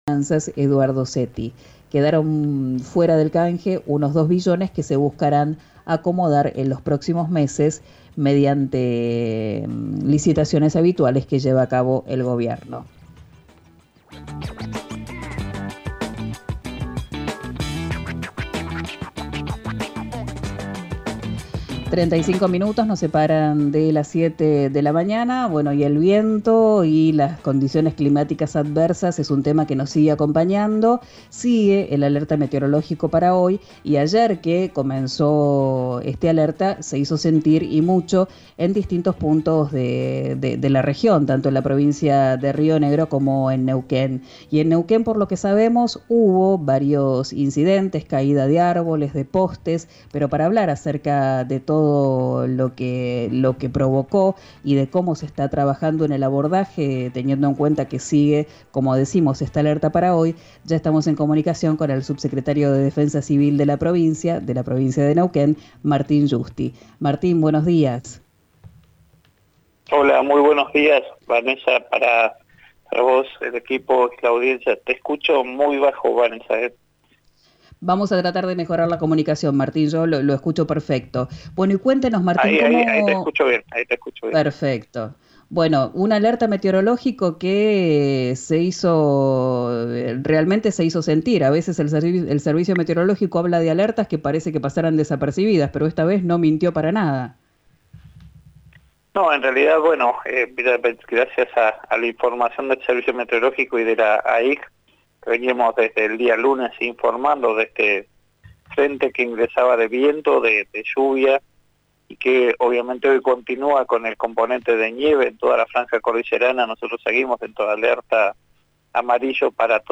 Escuchá al subsecretario de Defensa Civil y Protección Ciudadana, Martín Giusti, en RÍO NEGRO RADIO: